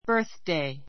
bə́ː r θdei バ ～ す デイ